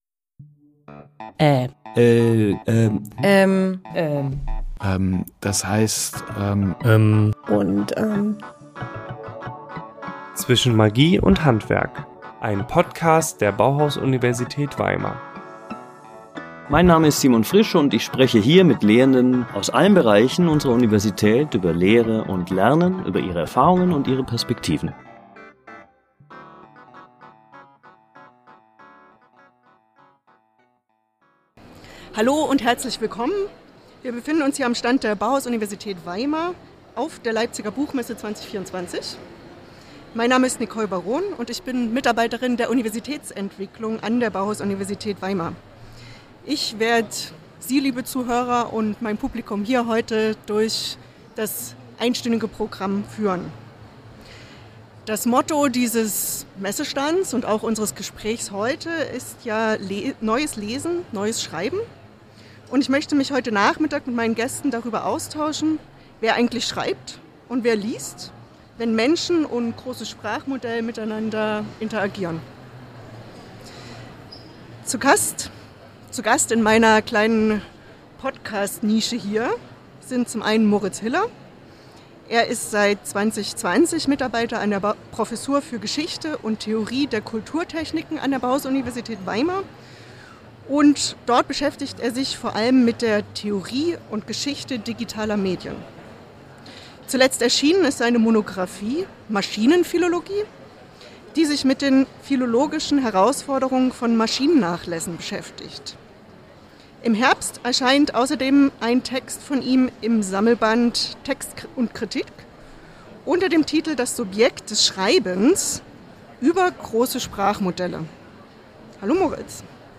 Gespräch
auf der Leipziger Buchmesse ~ Zwischen Magie und Handwerk Podcast